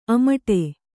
♪ amaṭe